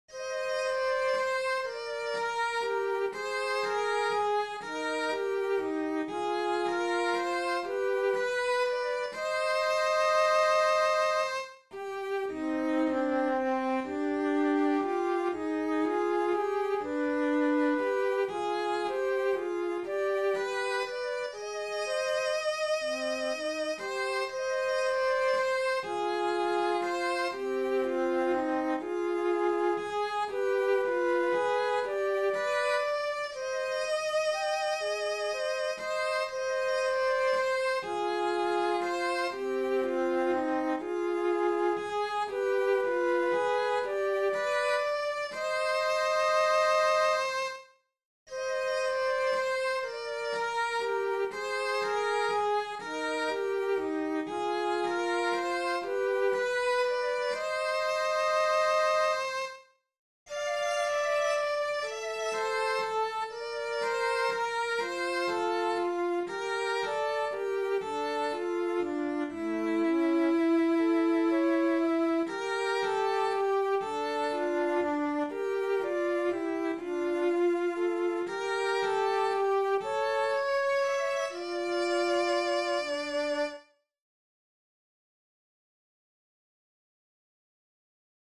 Kun-pimeaan-veit-sello-ja-huilu.mp3